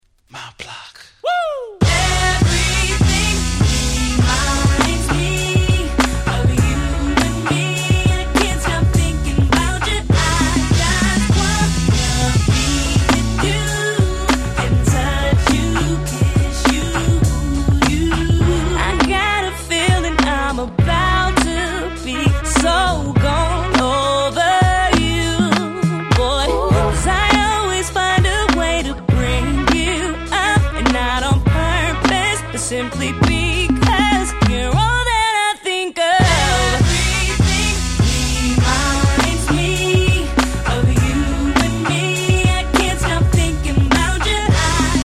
系の込み上げ系UPナンバー！！
キャッチー系 00's